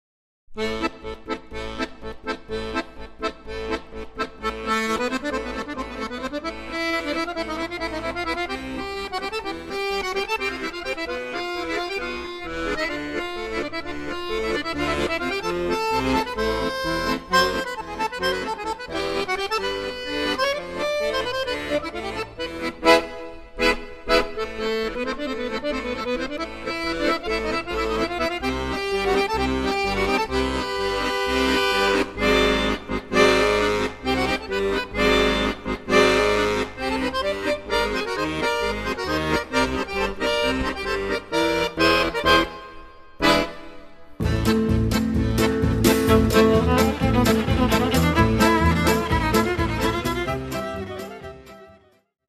fisarmonica
sax soprano e clarinetto
viola
chitarra
contrabbasso
batteria
percussioni
jazz, musica mediterranea, popolare e tango